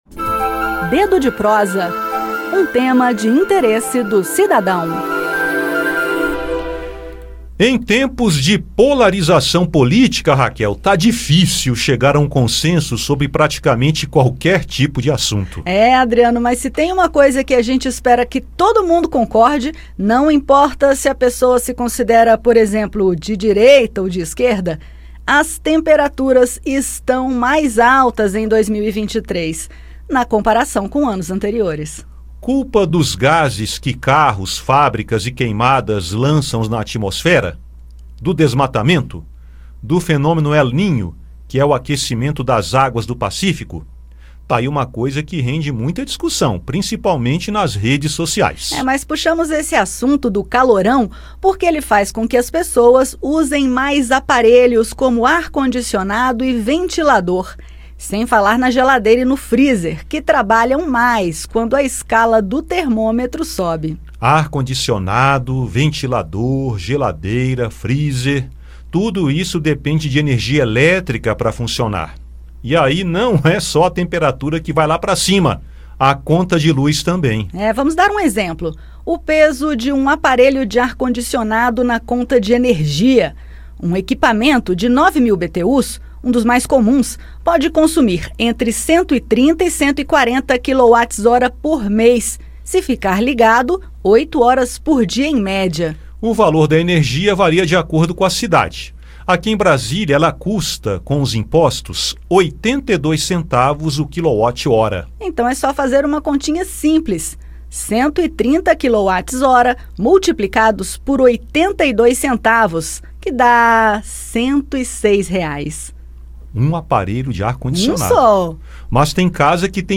Programa diário com reportagens, entrevistas e prestação de serviços